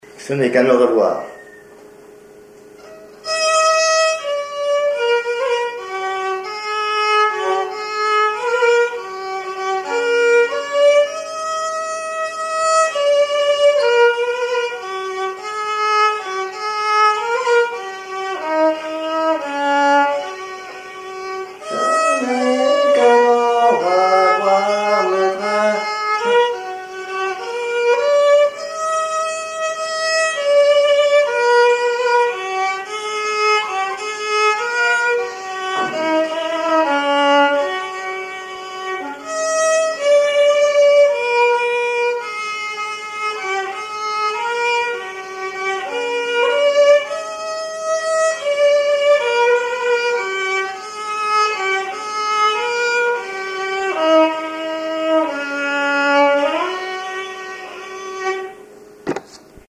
Mémoires et Patrimoines vivants - RaddO est une base de données d'archives iconographiques et sonores.
violoneux, violon,
Genre strophique
Pièce musicale inédite